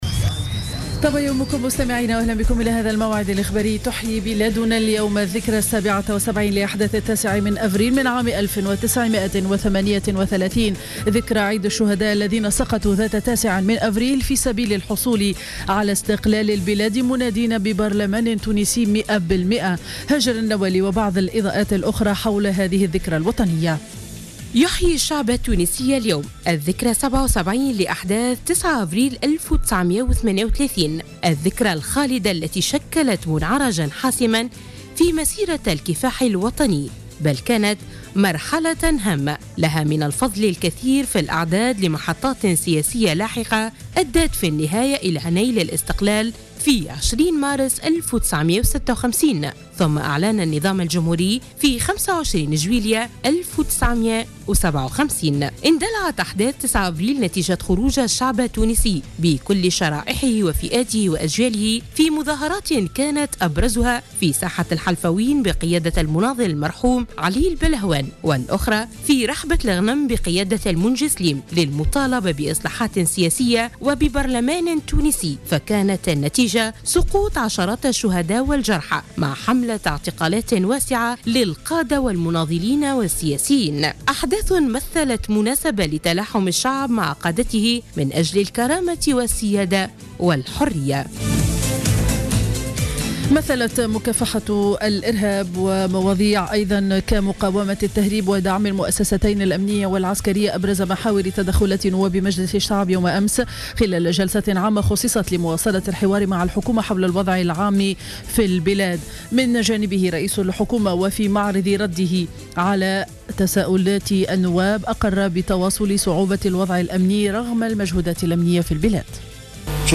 نشرة أخبار السابعة صباحا ليوم الخميس 9 أفريل 2015